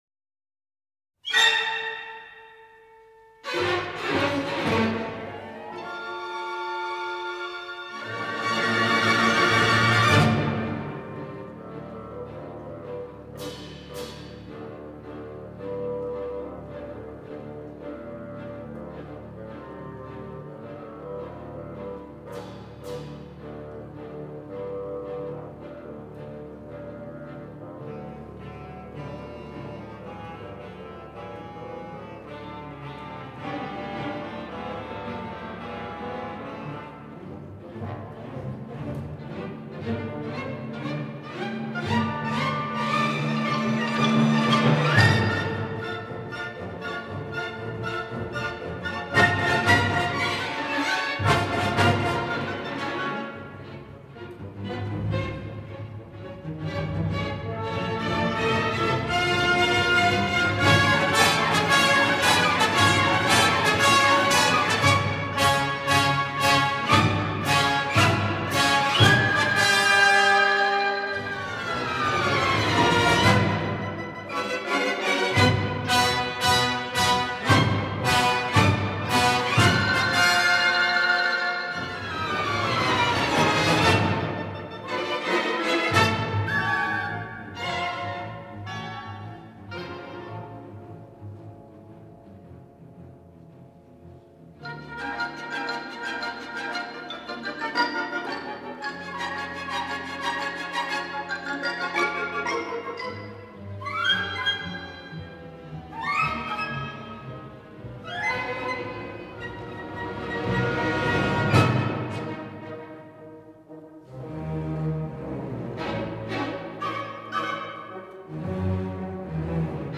БАБА ЯГА симфоническая картинка